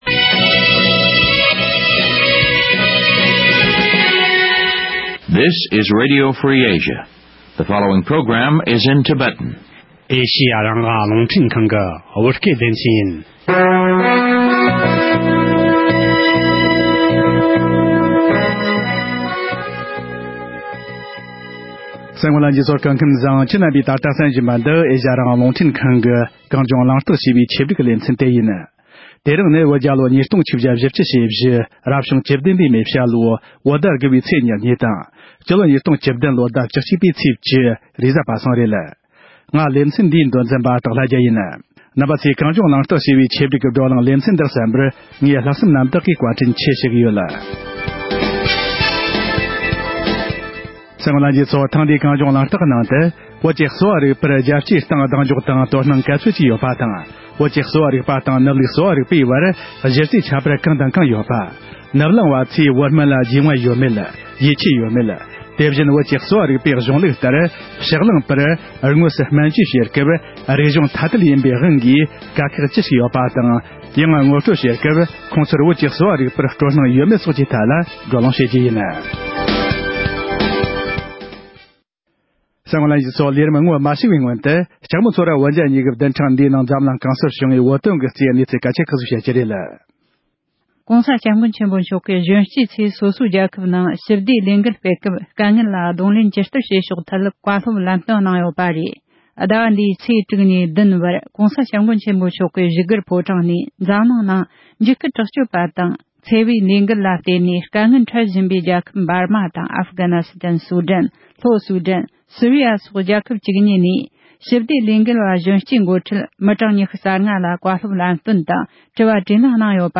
བོད་ཀྱི་གསོ་བ་རིག་པར་རྒྱལ་སྤྱིའི་སྟེང་གདེང་འཇོག་དང་དོ་སྣང་ག་ཚོད་ཡོད་པ། དེ་བཞིན་བོད་ཀྱི་གསོ་བ་རིག་པ་དང་ནུབ་ལུགས་གསོ་བ་རིག་པའི་བར་གཞི་རྩའི་ཁྱད་པར་སོགས་ཀྱི་ཐད་གླེང་མོལ།